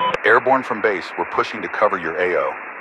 Radio-pilotNewFriendlyAircraft3.ogg